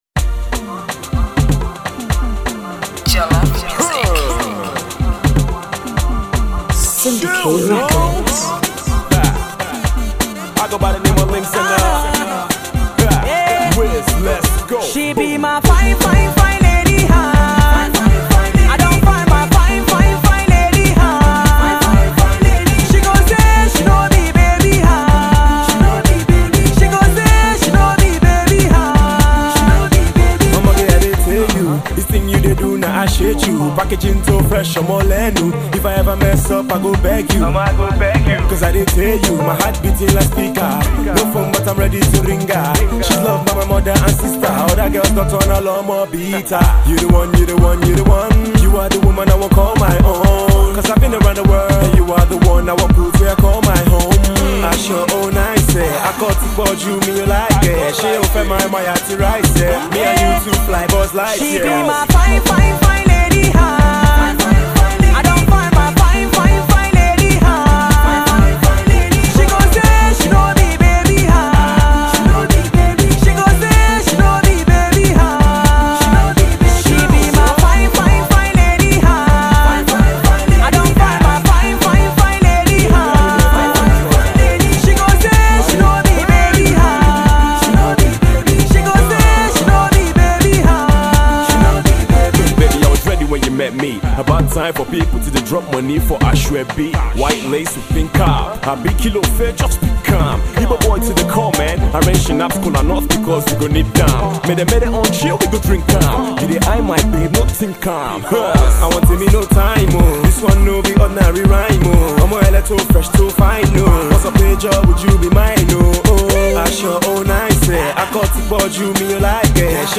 sweet dance floor vibes
Soukous-infused